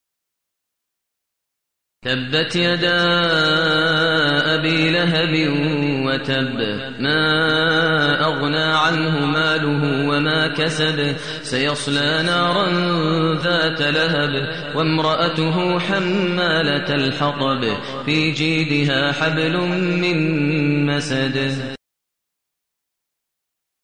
المكان: المسجد النبوي الشيخ: فضيلة الشيخ ماهر المعيقلي فضيلة الشيخ ماهر المعيقلي المسد